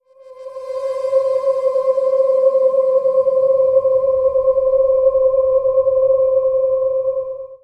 Index of /90_sSampleCDs/Trance_Formation/Atmospheric
32_Ghostly_C.WAV